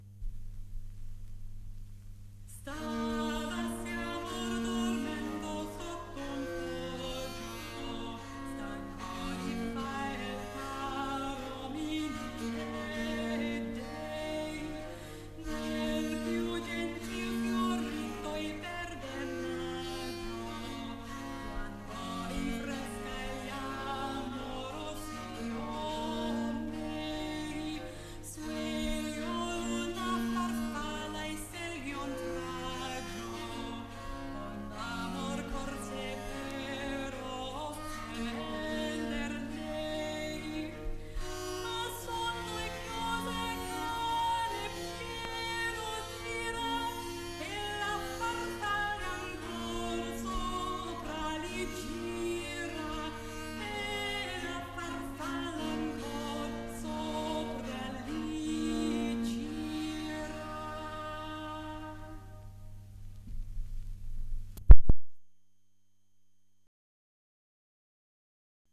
Tromboncino, Stavasi amor (frottola).mp3 — Laurea Magistrale in Culture e Tradizioni del Medioevo e del Rinascimento